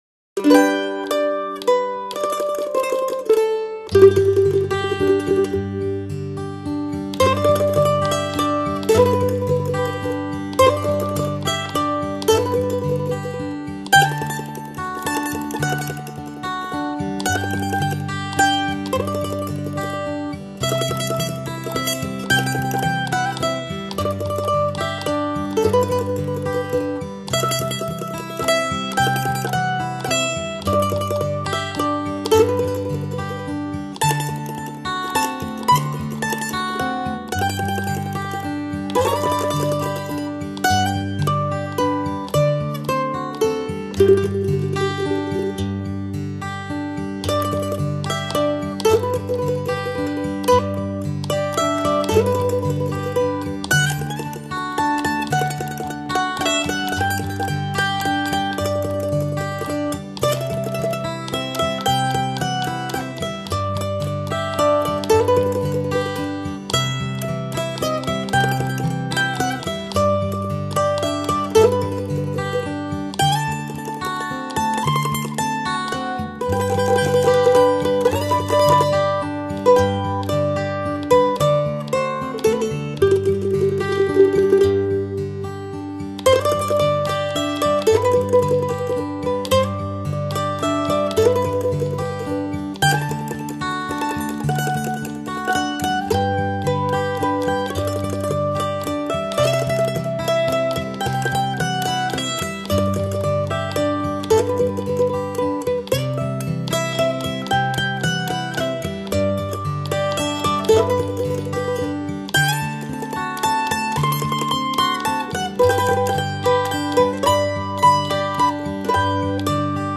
风格流派：New Age